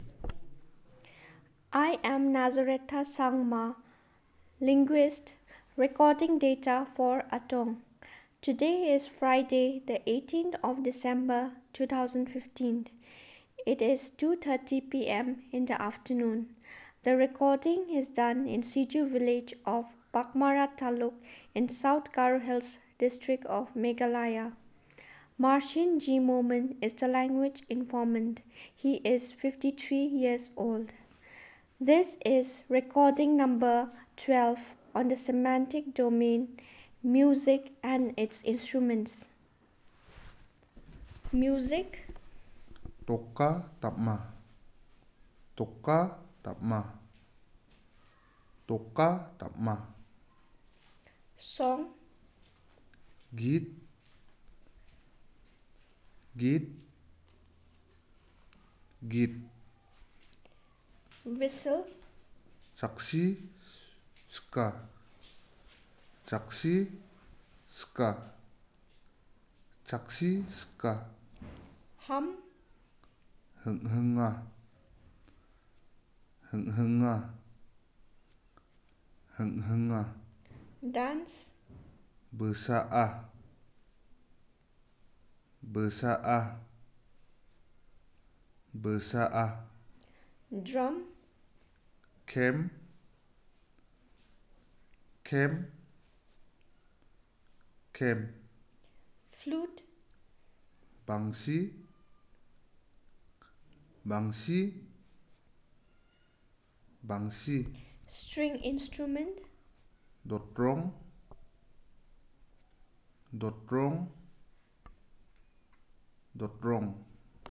Elicitation of words about music and musical instruments